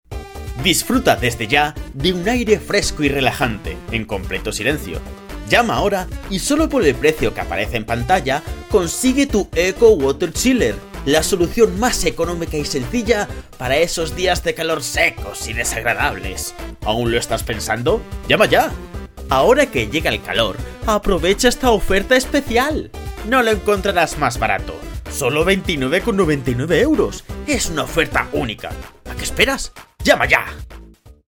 Demo Teletienda
Castellano neutro
Young Adult
Teletienda.MP3